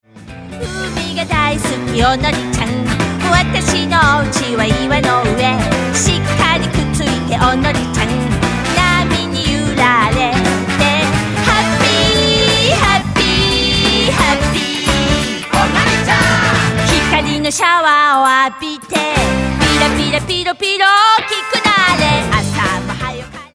【おどってみよう！体操＆ダンス】